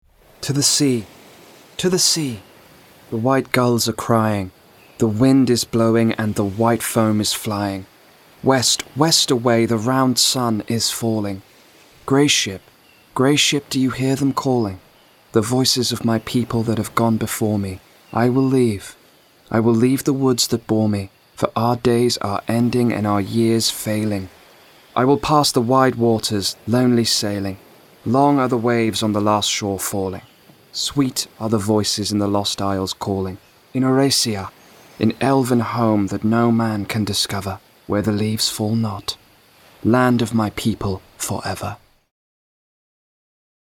• Male
LOTR. Audio Book, Narration, Soft spoken, Descriptive